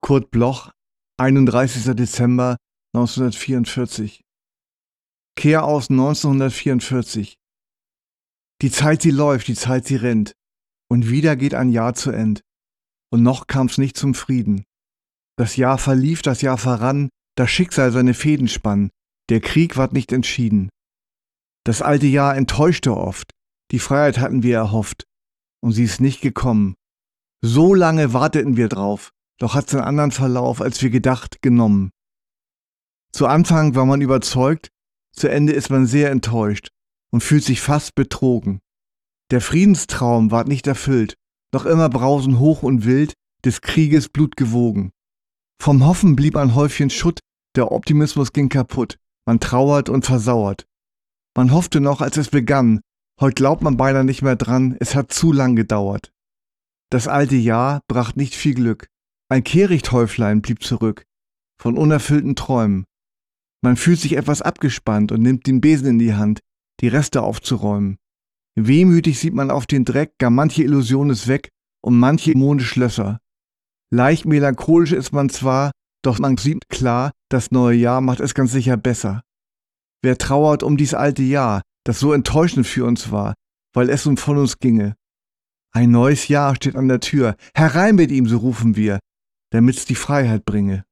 Slotdans 1944 voorgedragen door Heinz Strunk